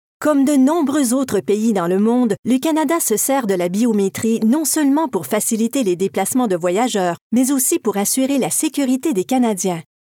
A clear, natural and trustworthy French Canadian female voice actress that has completed many high profiles projects in North America and abroad. Her voice range is between 20 and 40.
kanadisch-fr
Sprechprobe: Industrie (Muttersprache):